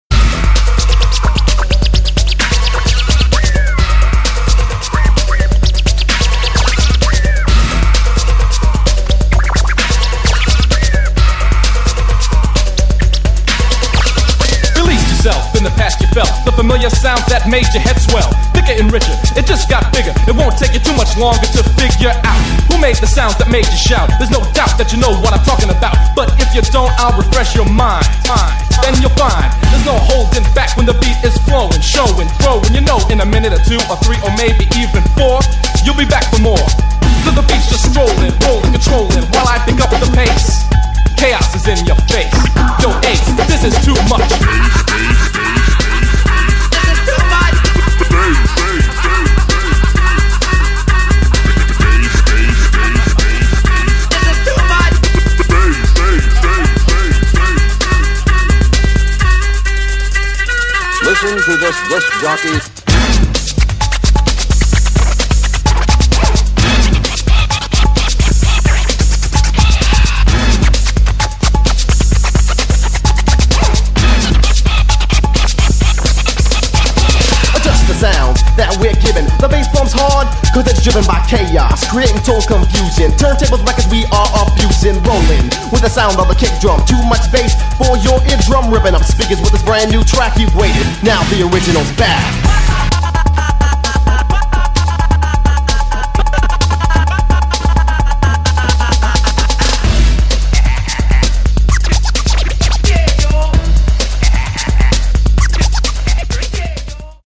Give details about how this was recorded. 12" vinyl EP